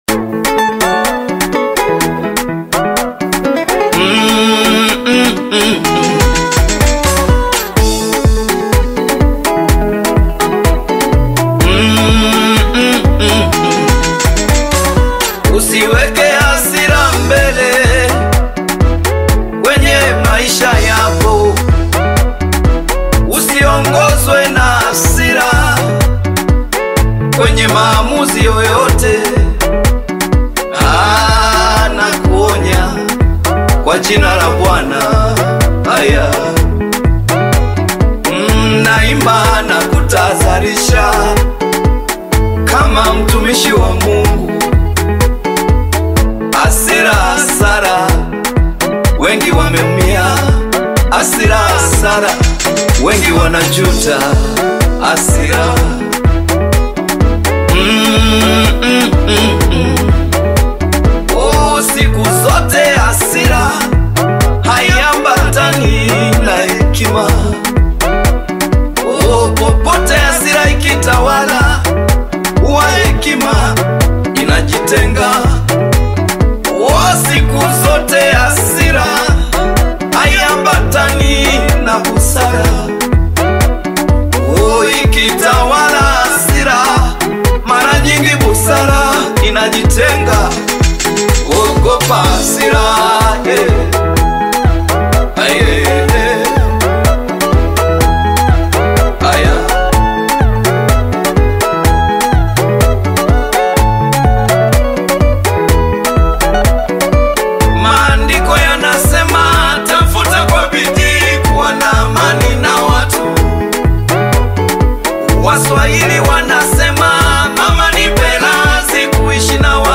Tanzanian gospel
Gospel song